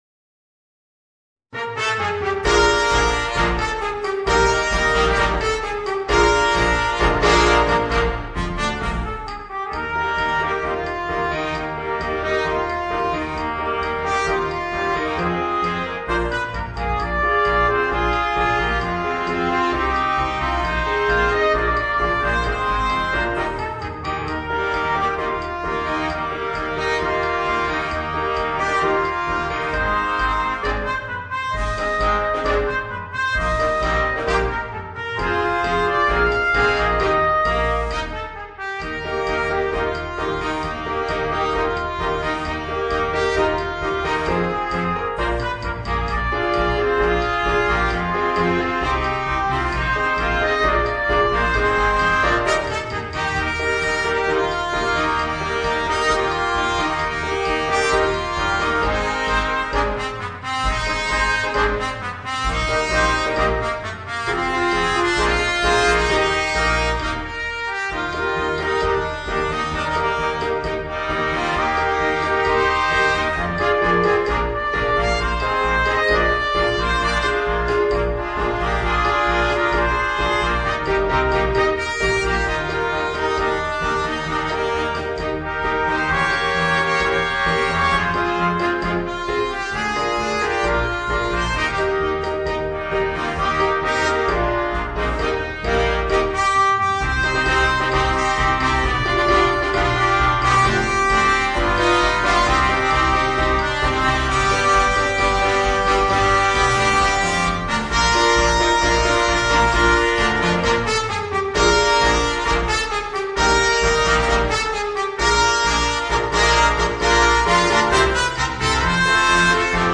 Voicing: 4 Trumpets